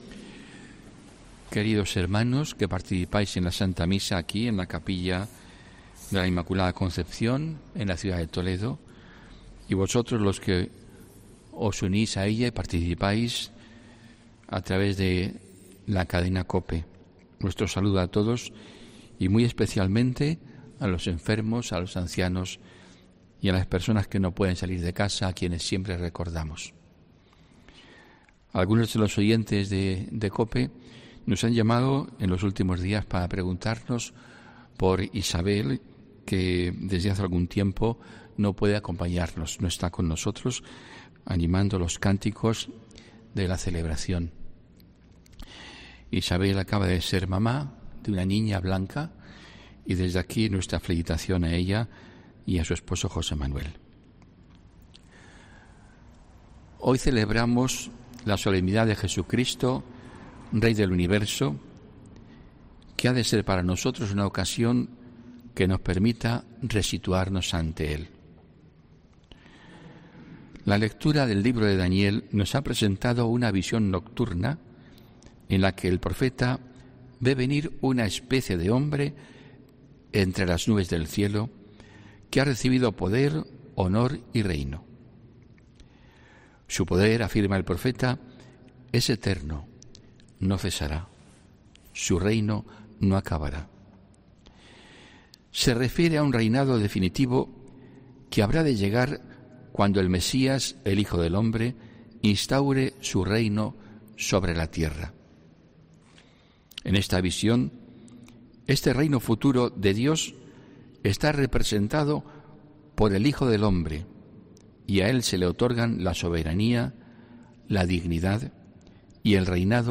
HOMILÍA 21 NOVIEMBRE 2021